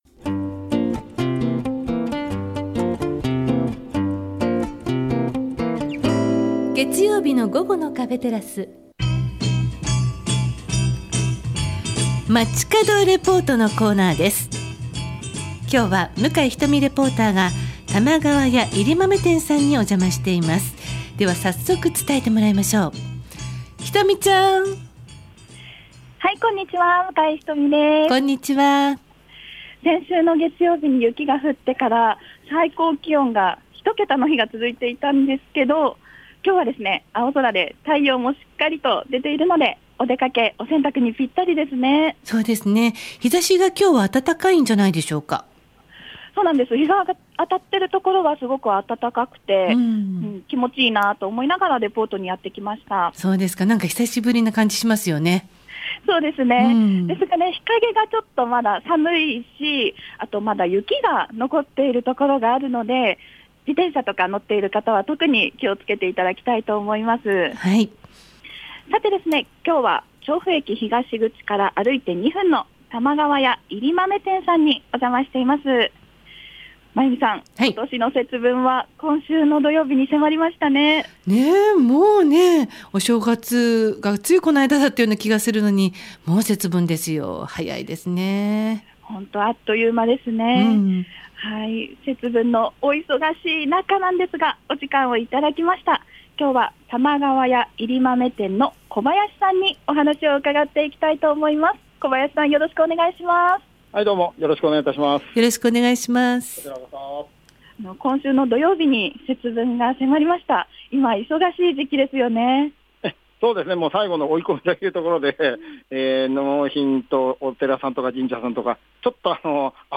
午後のカフェテラス 街角レポート